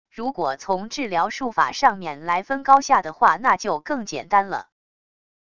如果从治疗术法上面来分高下的话那就更简单了wav音频生成系统WAV Audio Player